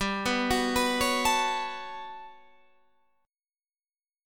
GM#11 chord